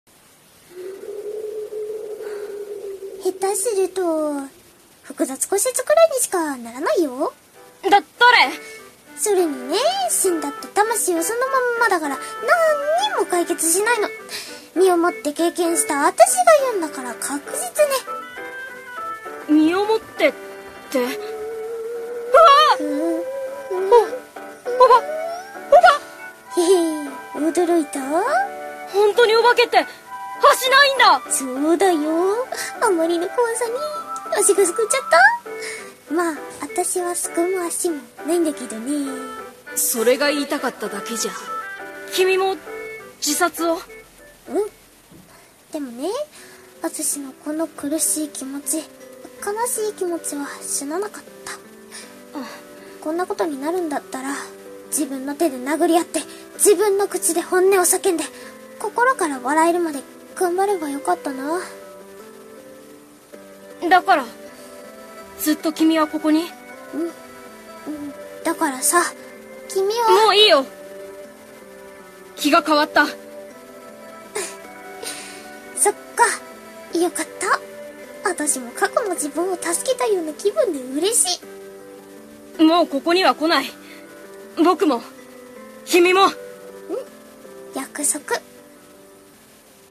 【コラボ声劇】半透明の人